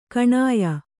♪ kaṇaya